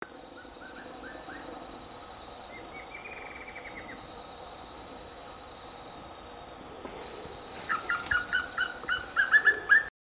Papinho-amarelo (Piprites chloris)
Nome em Inglês: Wing-barred Piprites
Localidade ou área protegida: Reserva Privada y Ecolodge Surucuá
Condição: Selvagem
Certeza: Fotografado, Gravado Vocal